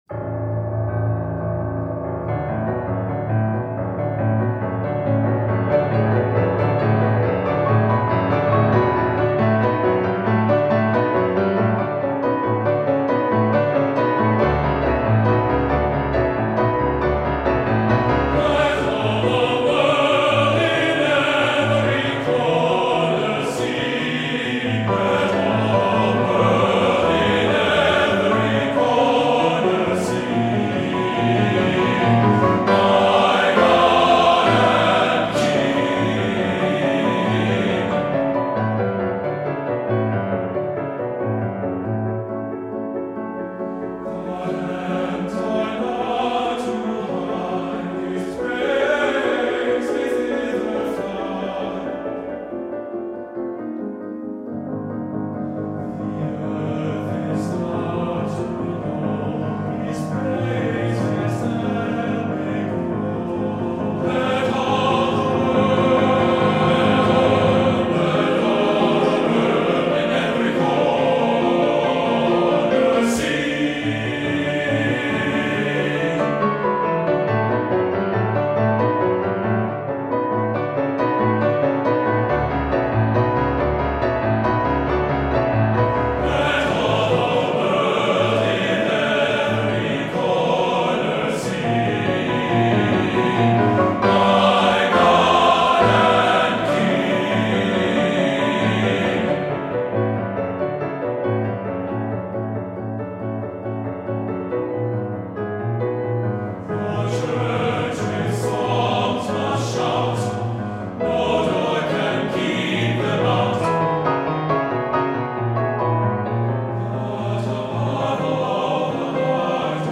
Voicing: TTBB and Piano 4 Hands